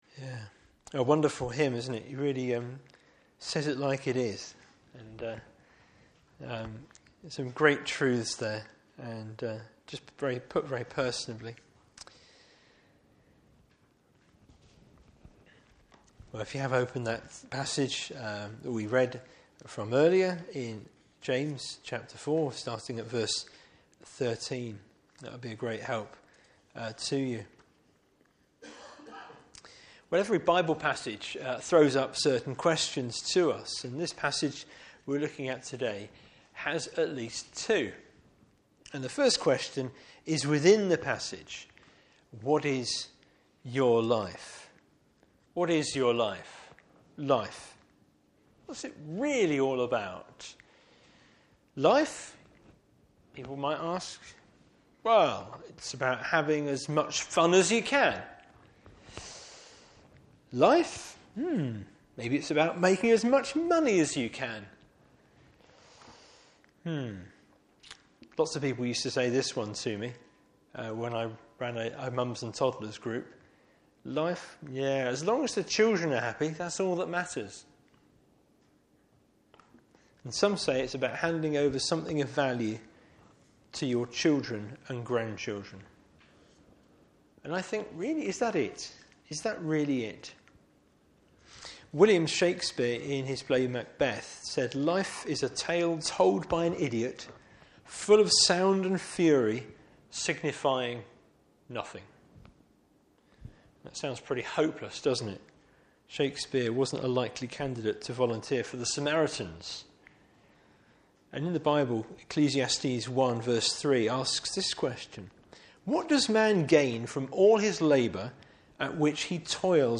Service Type: Evening Service Bible Text: James 4:13 – 5:6.